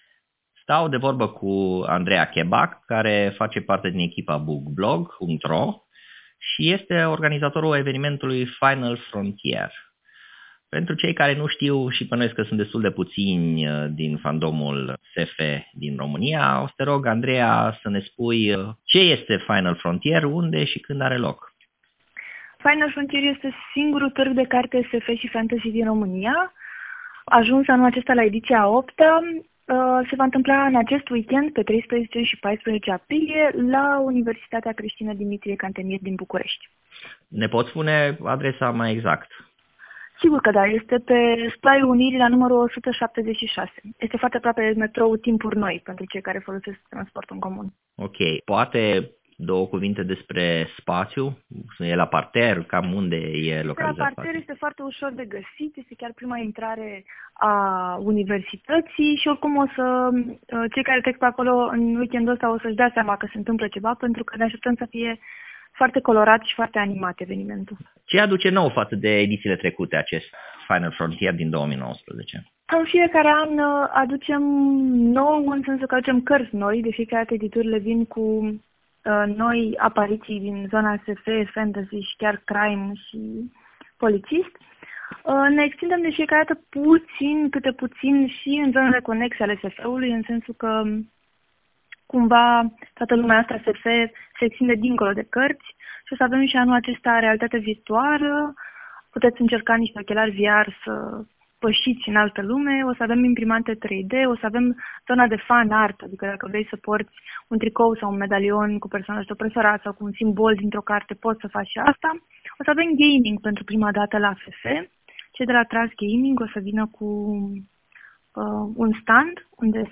Galaxia Imaginarului nr. 43 - discuție